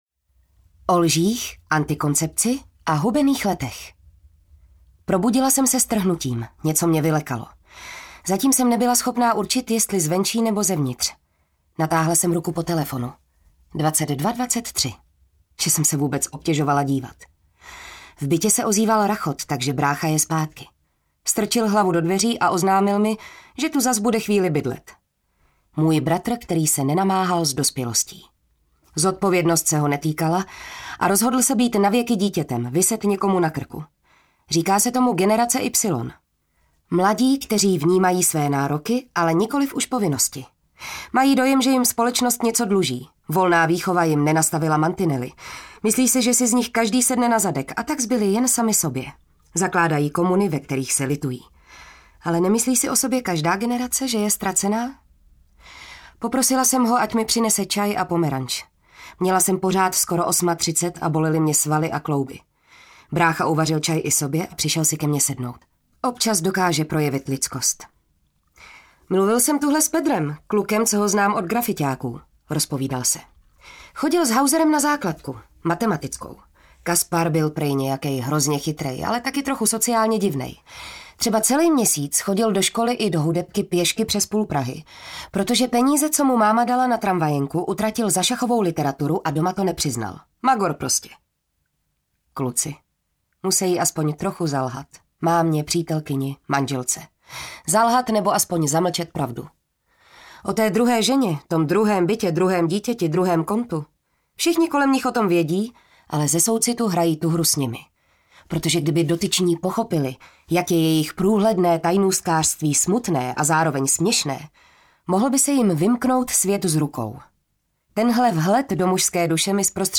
Teorie podivnosti audiokniha
Ukázka z knihy